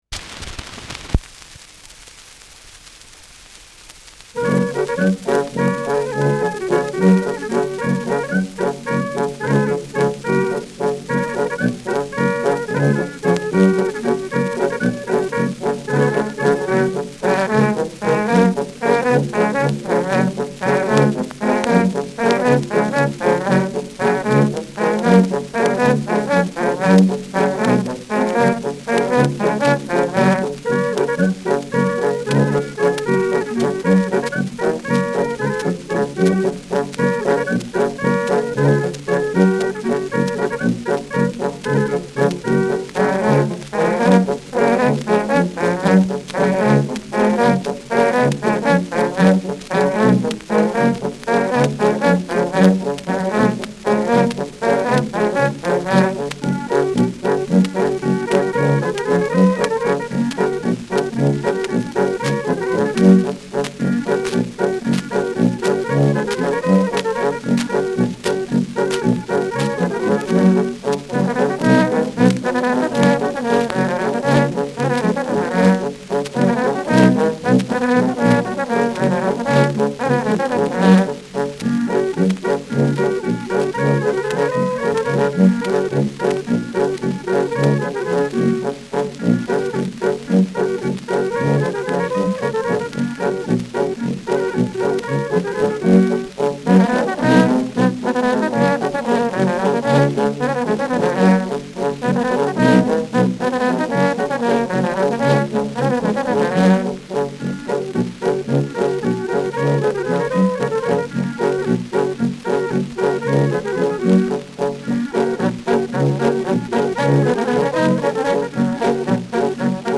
Schellackplatte
Gelegentlich leichtes Knacken
Ein Tonartwechsel erfolgt nicht.